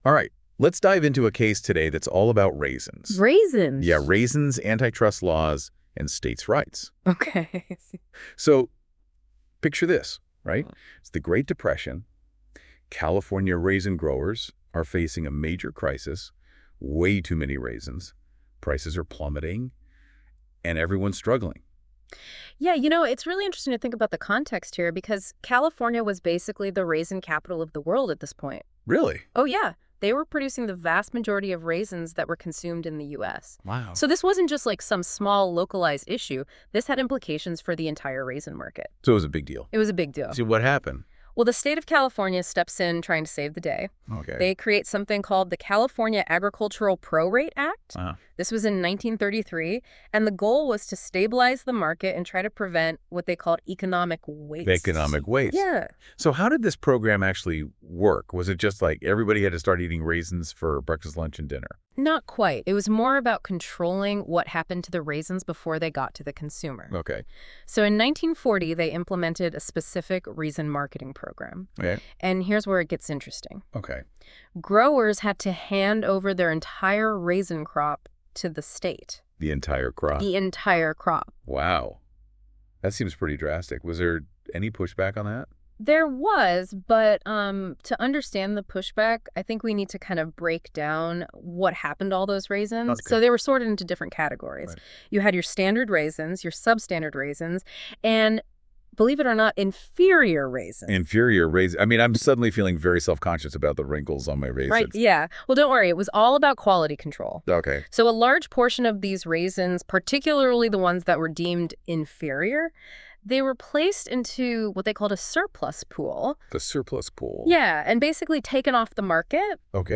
Listen to an audio breakdown of Parker v. Brown.